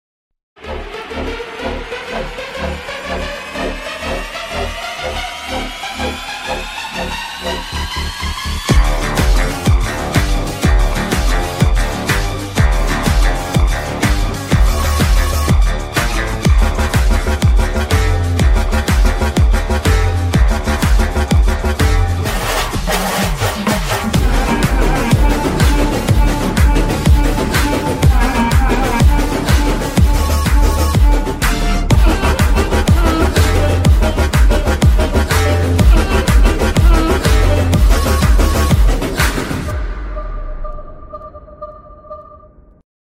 HD BGM Mix Ringtone